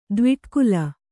♪ dviṭkula